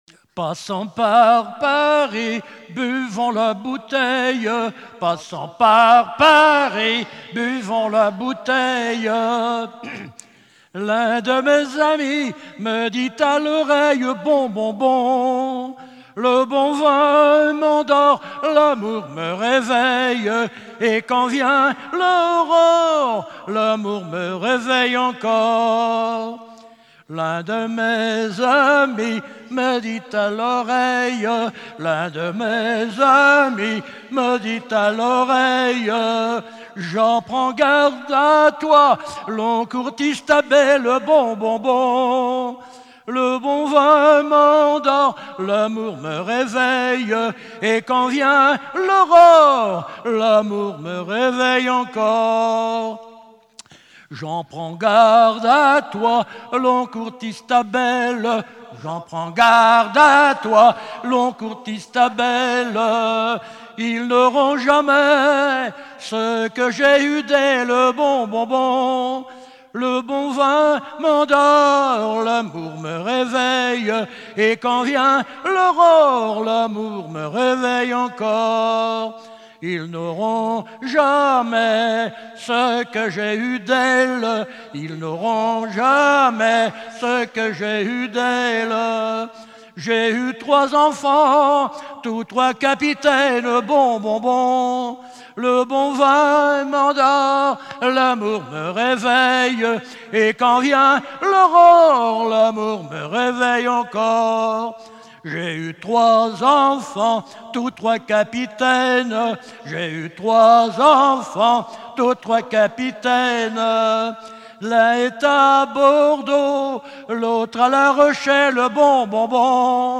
Festival de la chanson traditionnelle - chanteurs des cantons de Vendée
Pièce musicale inédite